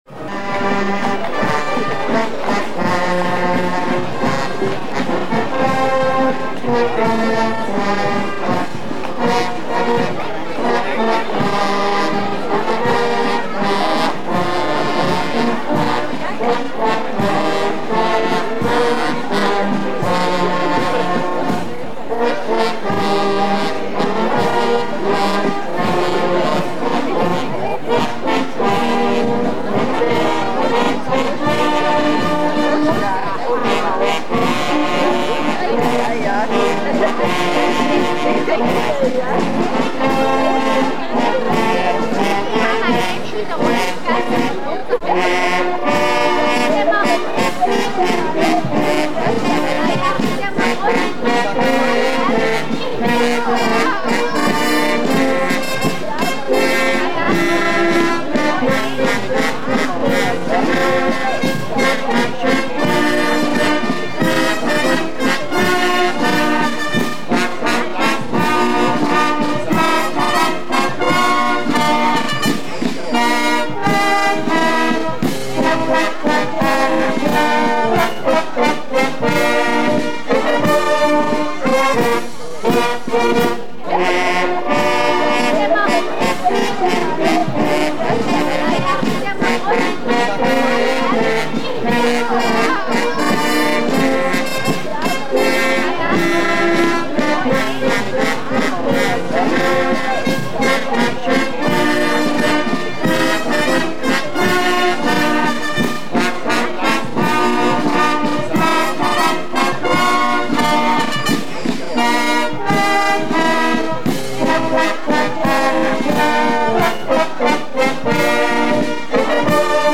Prozessionsmarsch